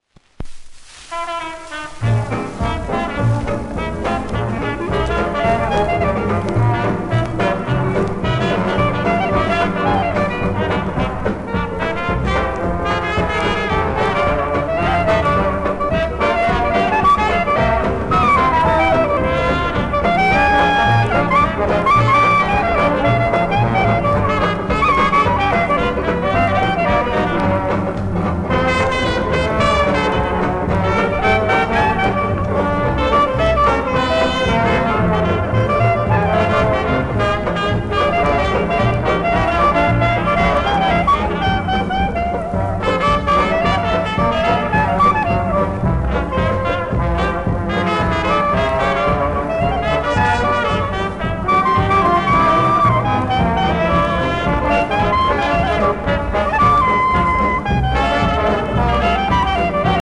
盤質:B *やや溝荒
1951年頃の録音